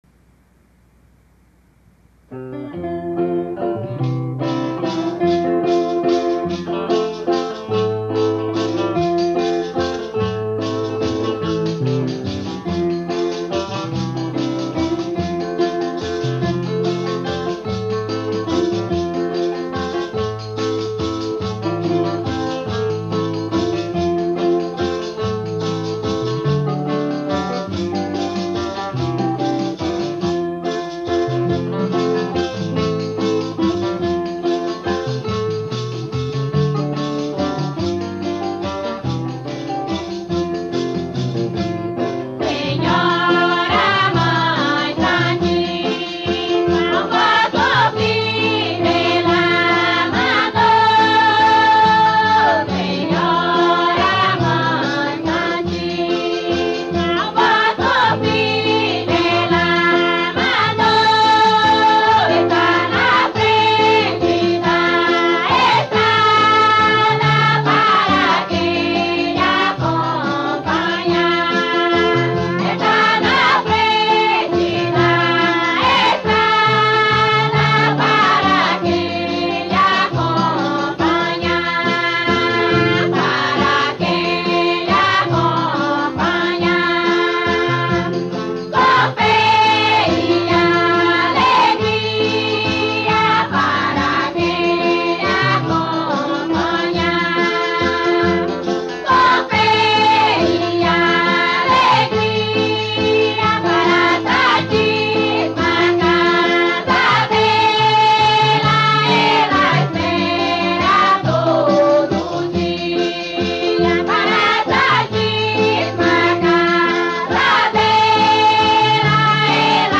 valsa